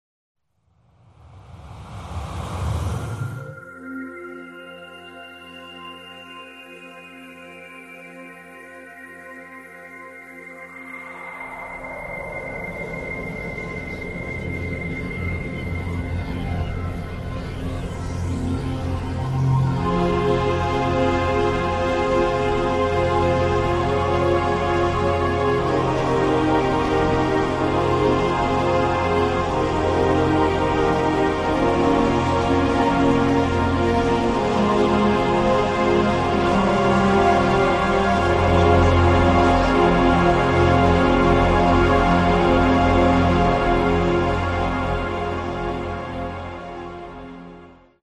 Category: Melodic Hard Rock
Vocals
Guitars
Bass
Drums